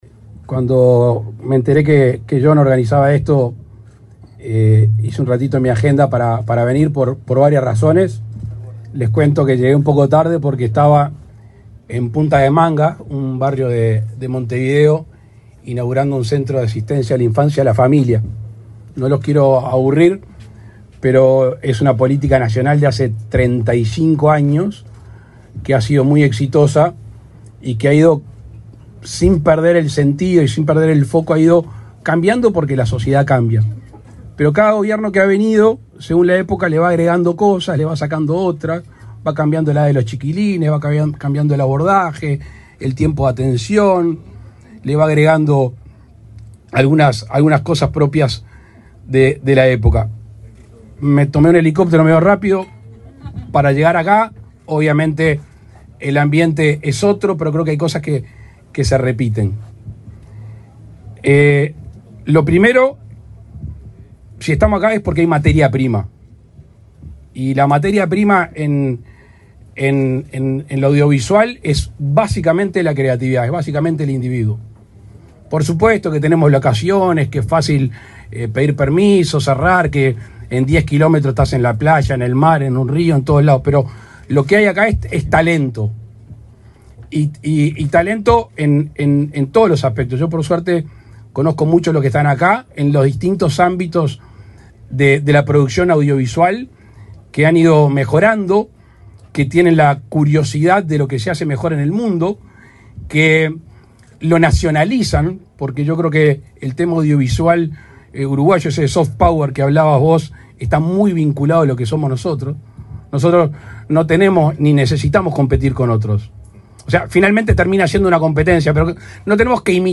Palabras del presidente Luis Lacalle Pou
Palabras del presidente Luis Lacalle Pou 19/07/2024 Compartir Facebook X Copiar enlace WhatsApp LinkedIn El presidente Luis Lacalle Pou, encabezó, este viernes 19 en Maldonado, la presentación del Fondo Cinematográfico Pfeffer del Sur, realizado en la localidad de José Ignacio.